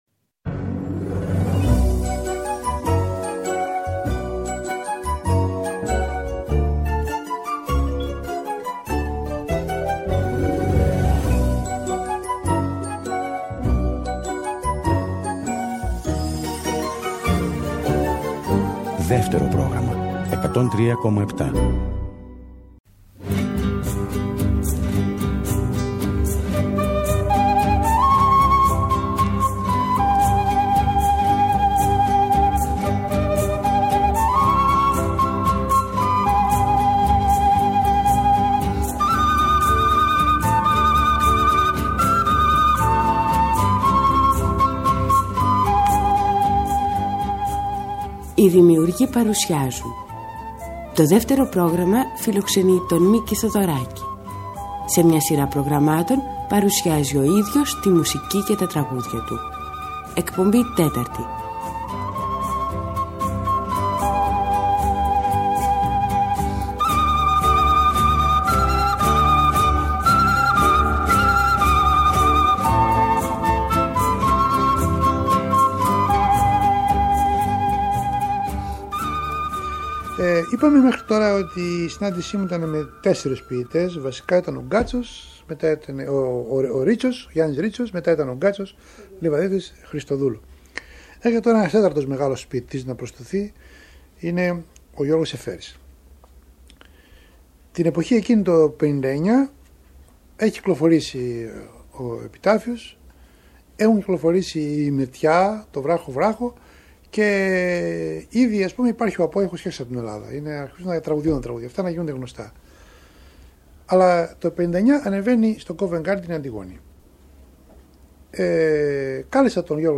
τον Μίκη Θεοδωράκη, μόνο μπροστά στο μικρόφωνο να ξετυλίγει με τον δικό του τρόπο το κουβάρι των αναμνήσεών του και να αφηγείται τη ζωή του.